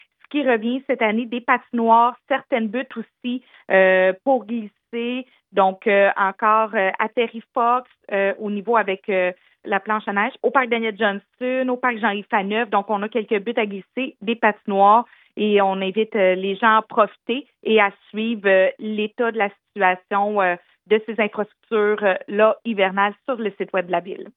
Julie Bourdon, mairesse de Granby